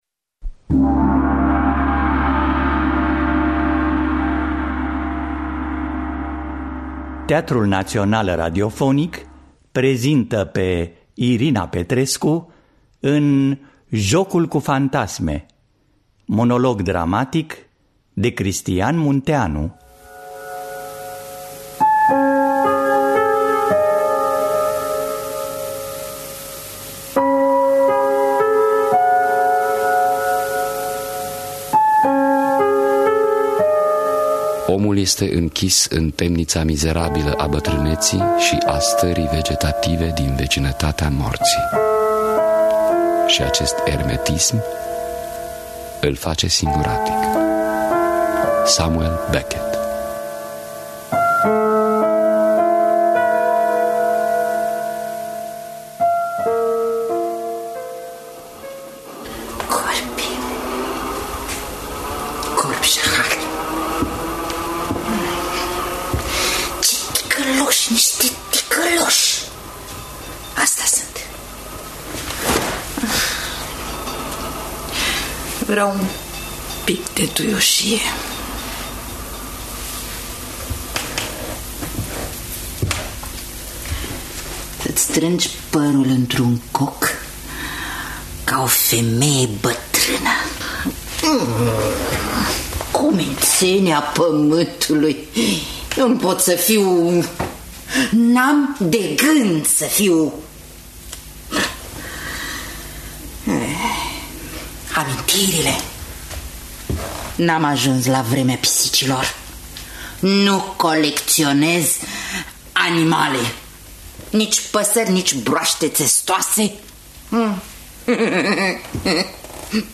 Interpretează: Irina Petrescu.
Jocul cu fantasme monolog dramatic de Cristian Munteanu.mp3